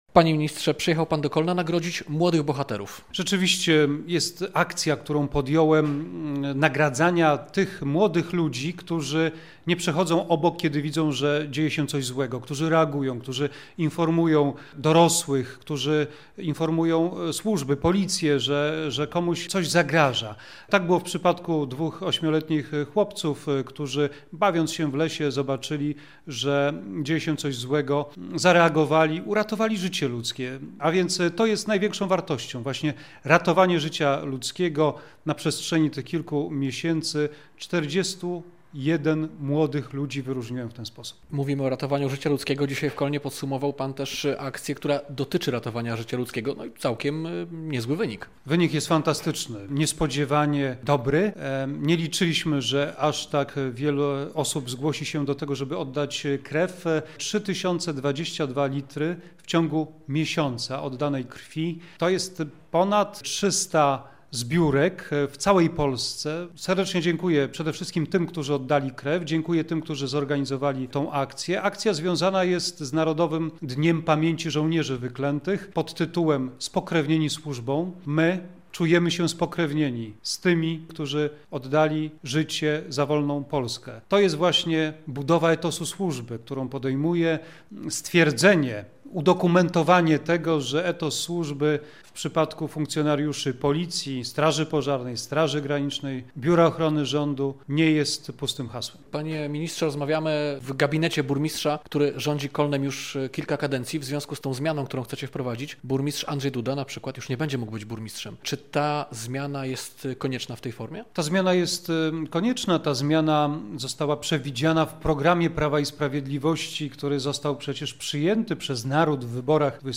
Radio Białystok | Gość | Mariusz Błaszczak -